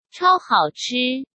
中国語音声の発音付きなのでチェックしながら勉強できるよ！